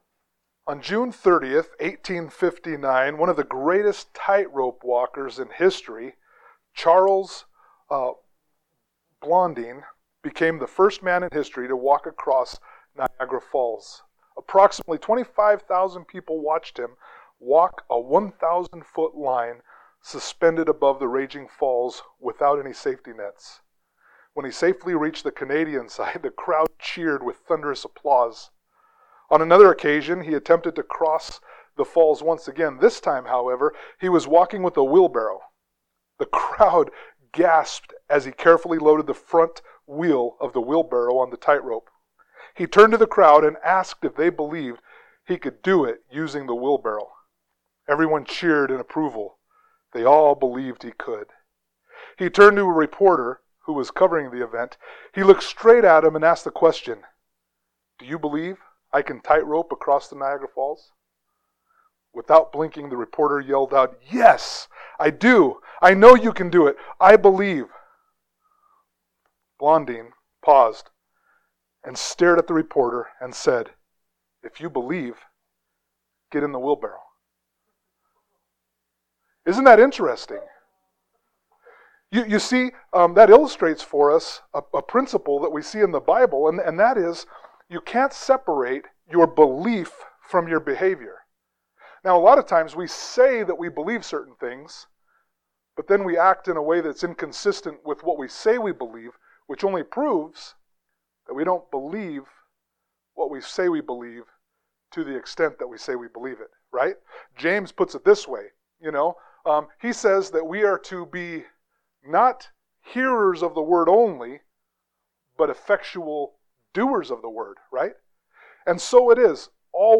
Romans 12:1-2 Service Type: Sunday Morning Worship « Romans 11:33-36